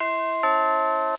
bing.wav